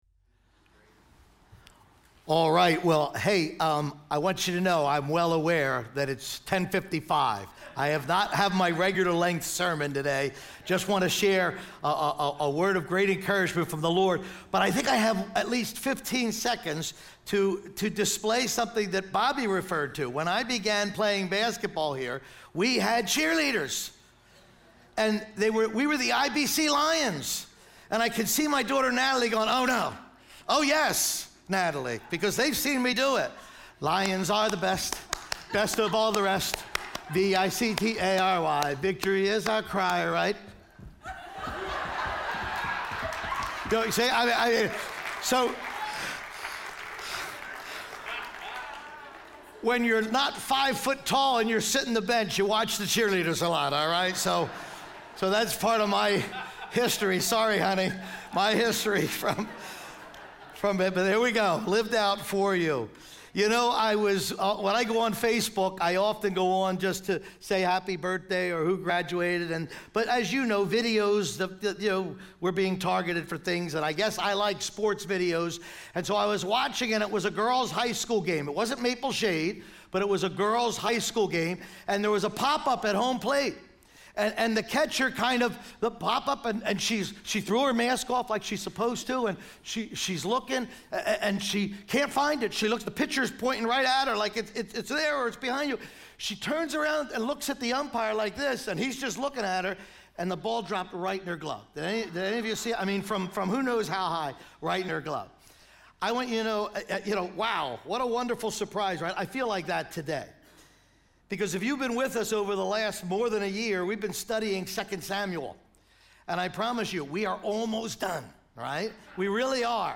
100th Anniversary Celebration Service!